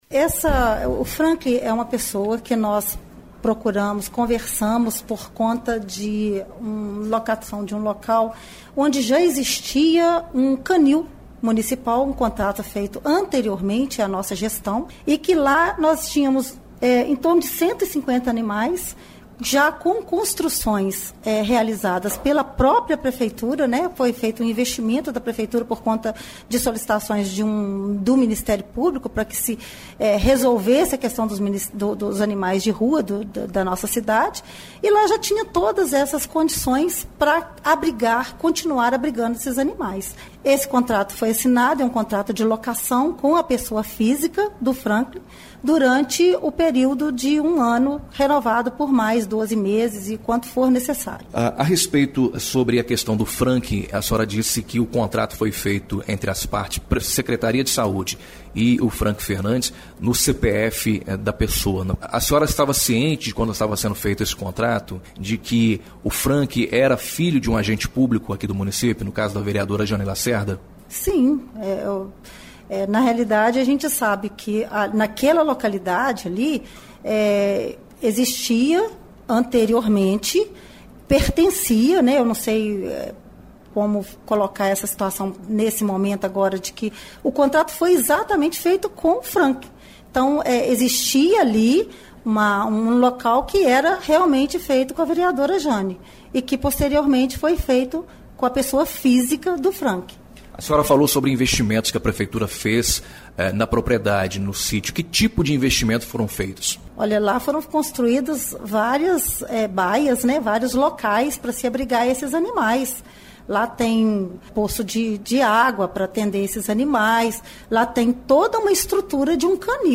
No áudio abaixo — a Secretária Municipal de Saúde — Dulcineia Tinassi justifica a assinatura do contrato.